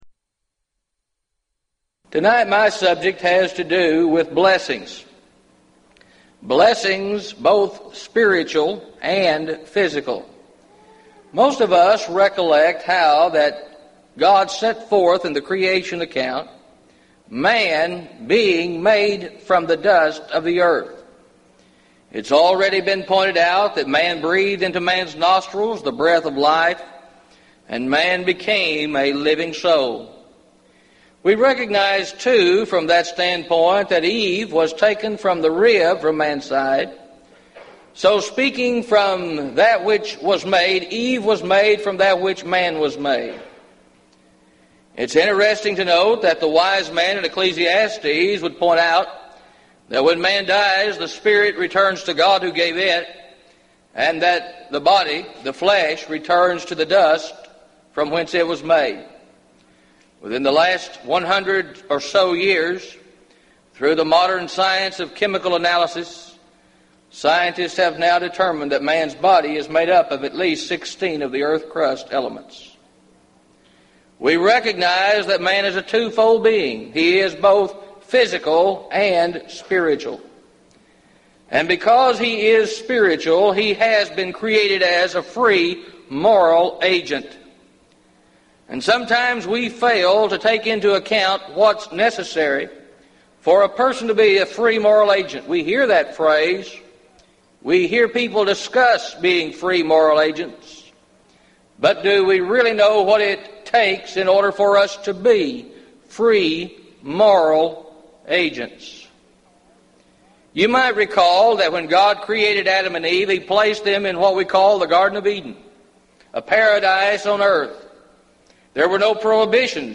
Event: 1998 Gulf Coast Lectures